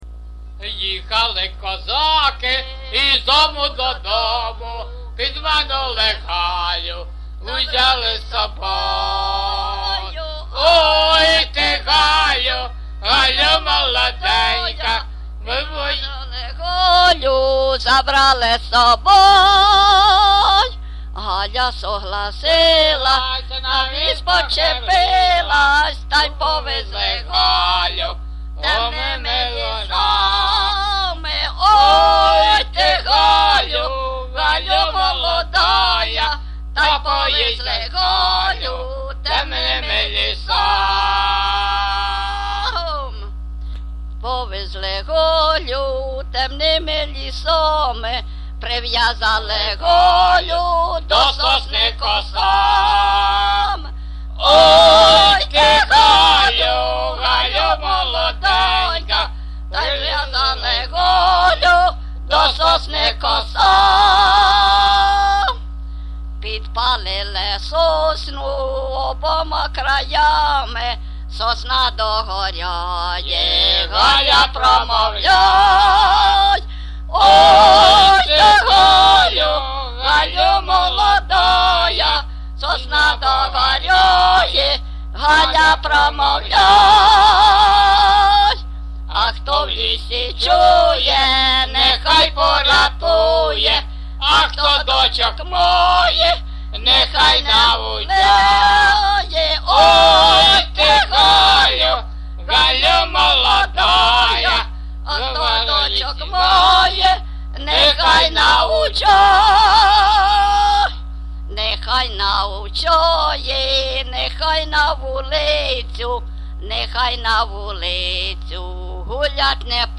ЖанрПісні з особистого та родинного життя, Козацькі
Місце записус. Нижні Рівні, Чутівський район, Полтавська обл., Україна, Слобожанщина